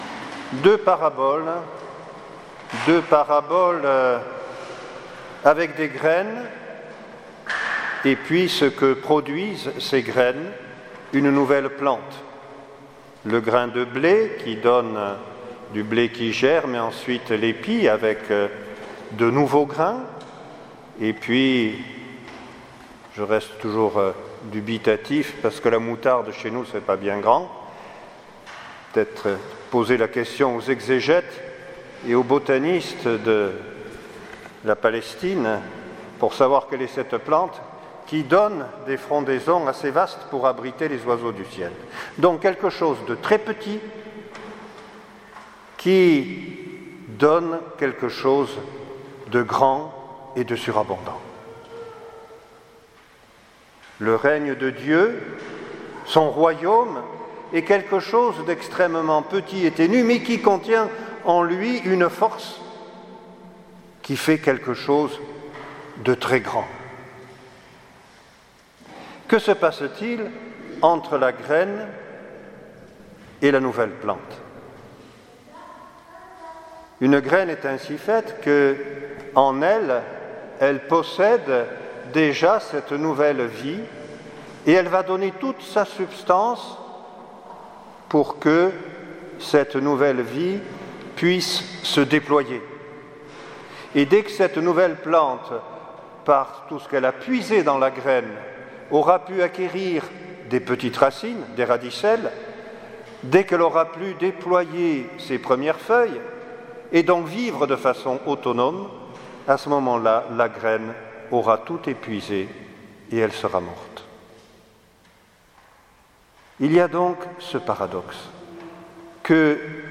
Homélie du 11ème dimanche du Temps Ordinaire 2018